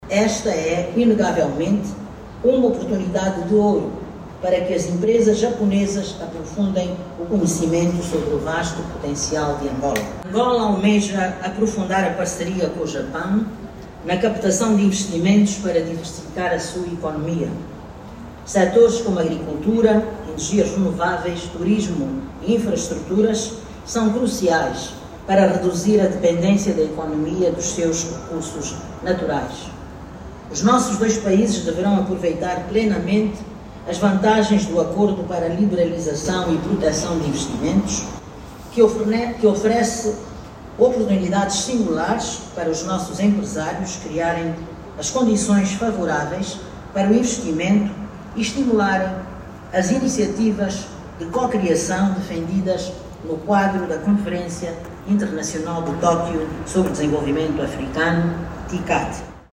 Em representação do Chefe de Estado angolano, Maria do Rosário Bragança discursou no Dia Nacional de Angola na Expo Japão, um momento marcado também por exibições de cultura e gastronomia.